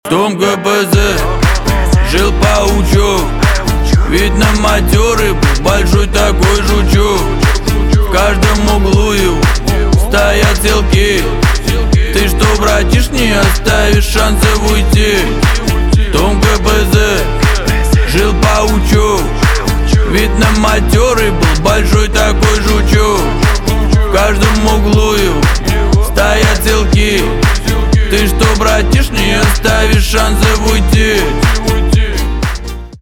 русский рэп
битовые , басы , гитара